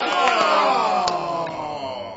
crowd_disappointed2.wav